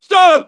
synthetic-wakewords
ovos-tts-plugin-deepponies_Homer Simpson_en.wav